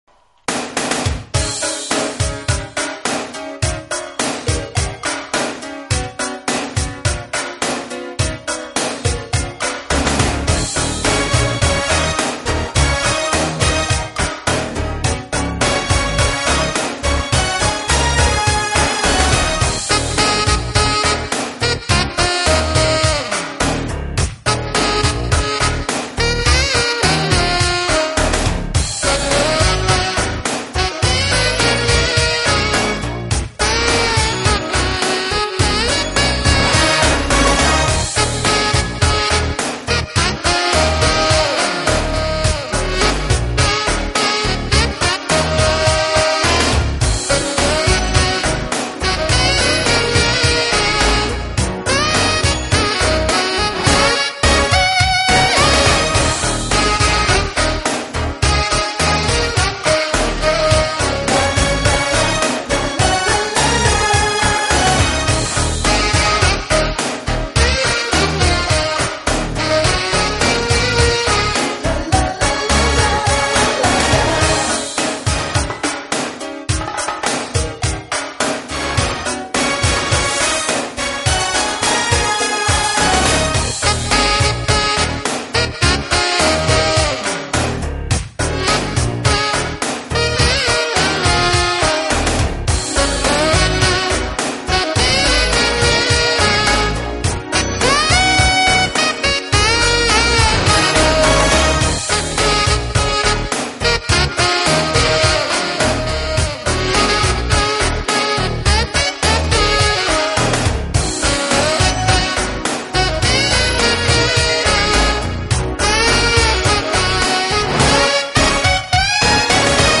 【爵士萨克斯】
的是次中音萨克斯，而后者演奏的是高音萨克斯，所以在听感方面前者的音色